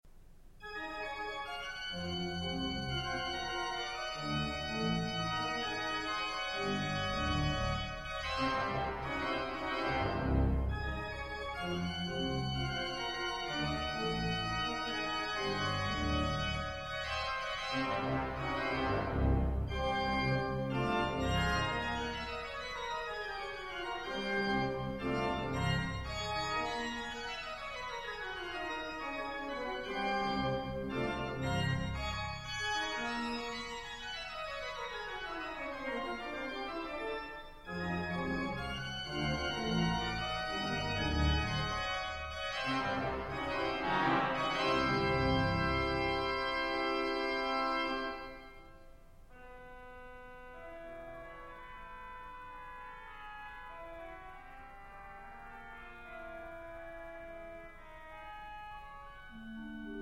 Scherzo - Lively   2:56